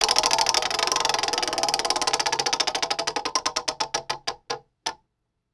roulette-wheel.mp3